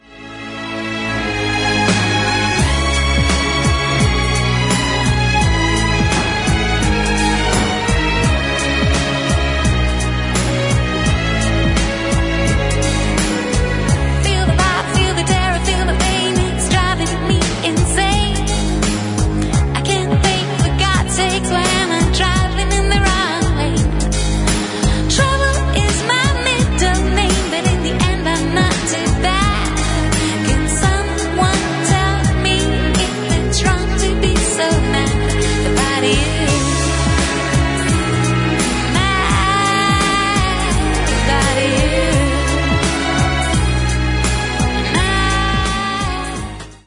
• Качество: 256, Stereo
лирические
романтические